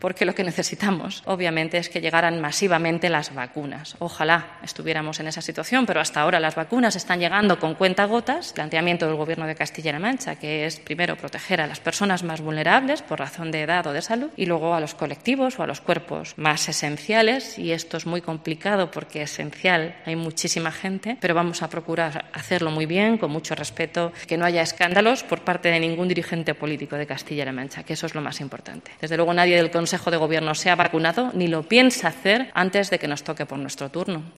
Declaraciones de la portavoz sobre vacunación Consejo de Gobierno